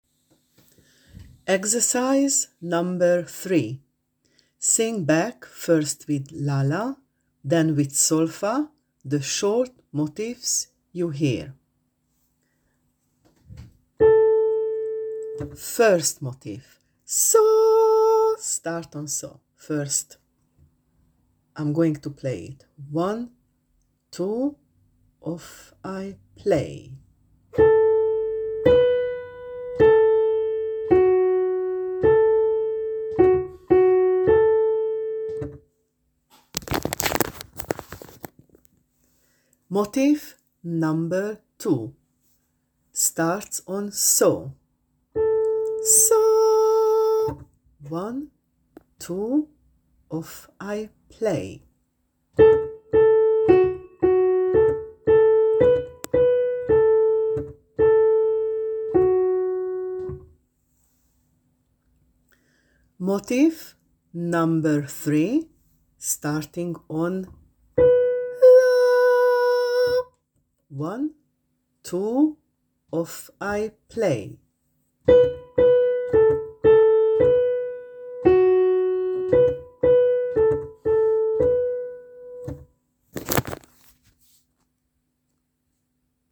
3.Sing back first with 'la-la' then with solfa the short motifs you hear. (3 motifs)